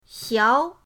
xiao2.mp3